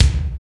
Smooth Kick Sound B Key 517.wav
Royality free kick drum sample tuned to the B note. Loudest frequency: 866Hz
smooth-kick-sound-b-key-517-g5z.mp3